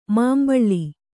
♪ māmbaḷḷi